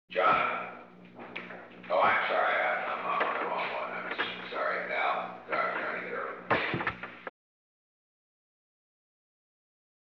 Conversation: 747-010
Recording Device: Oval Office
On July 19, 1972, President Richard M. Nixon and Alexander M. Haig, Jr. met in the Oval Office of the White House at an unknown time between 12:37 pm and 12:44 pm. The Oval Office taping system captured this recording, which is known as Conversation 747-010 of the White House Tapes.